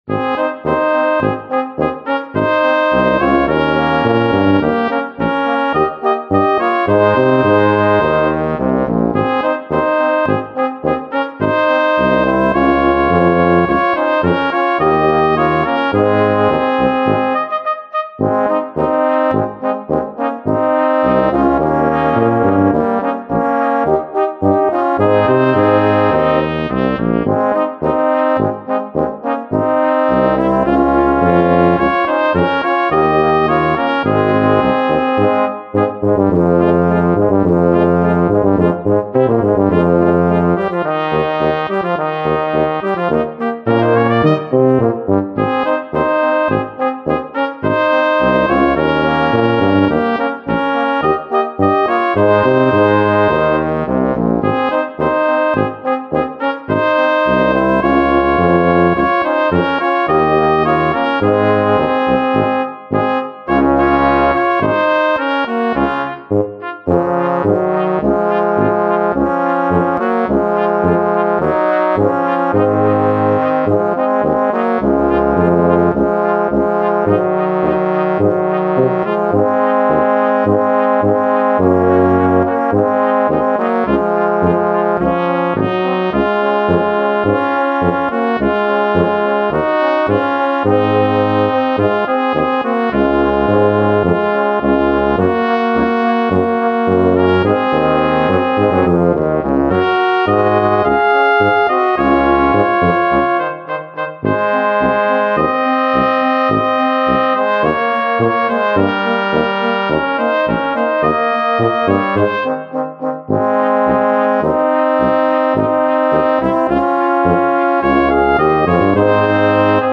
4er Besetzung
Polka
Vereinfachte 4er